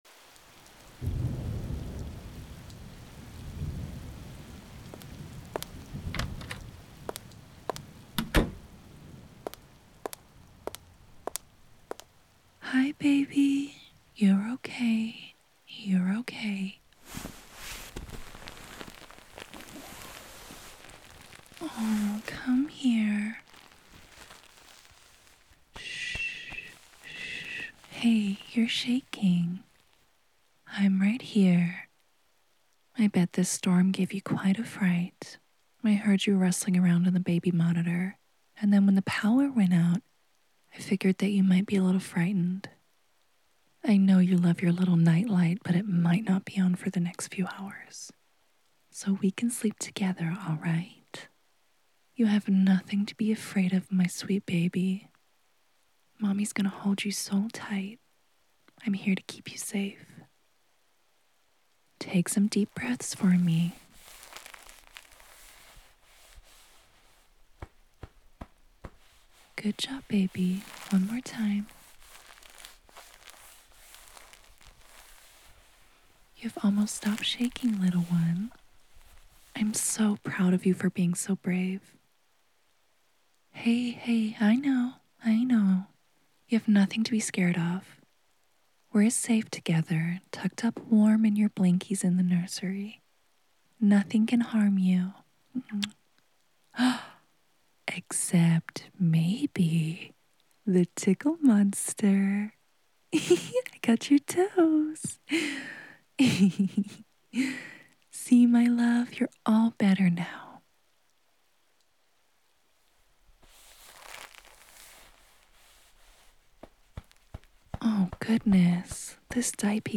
A sweet, loving, and nurturing episode to help you drift to sleep.